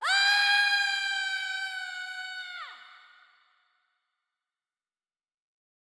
Tracer Scream Sound Button - Free Download & Play